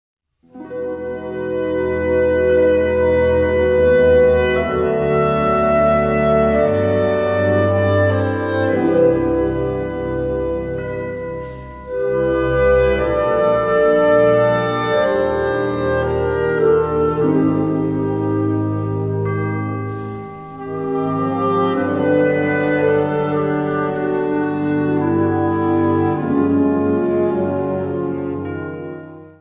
Subcategorie Hedendaagse blaasmuziek (1945-heden)
Bezetting Ha (harmonieorkest)